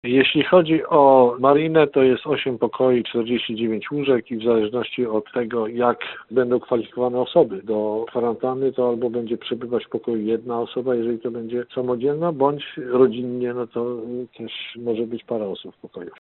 Mówi komendant Straży Miejskiej w Tarnobrzegu, Robert Kędziora.